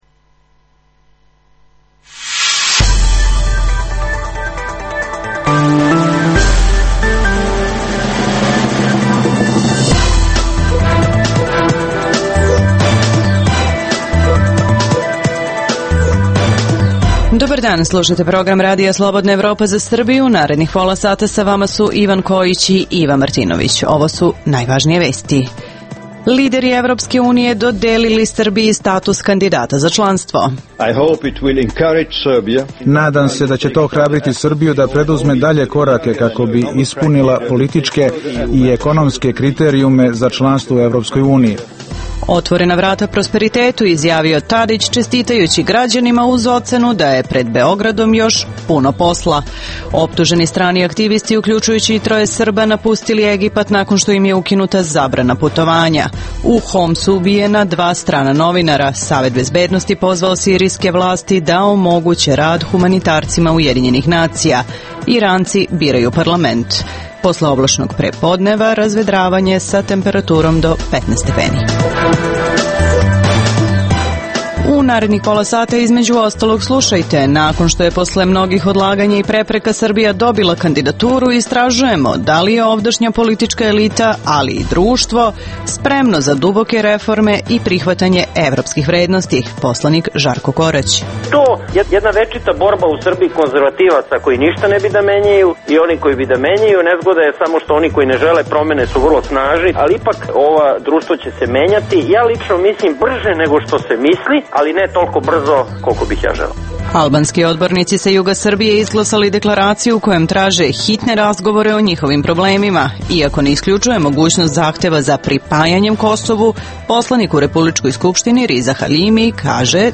Za RSE govori jedini albanski poslanik u Skupštini Srbije Riza Haljimi.